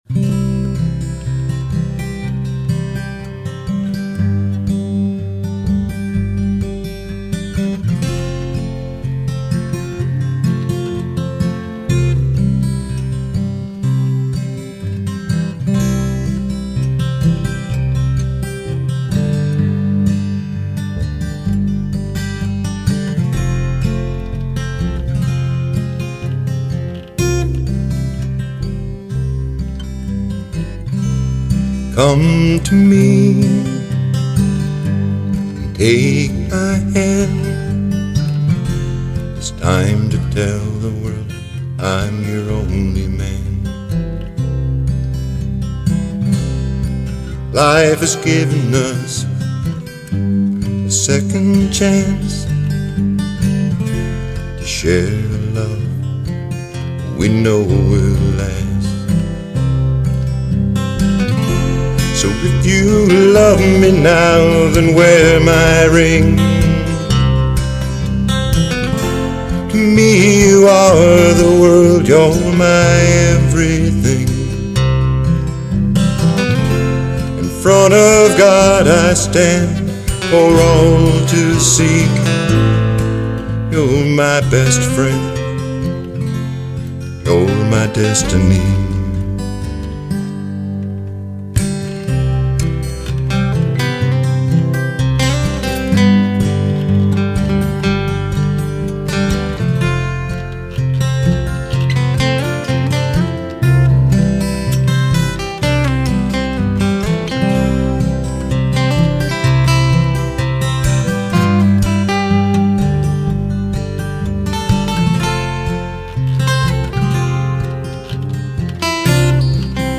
Lead Guitar
Bass Guitar
Percussion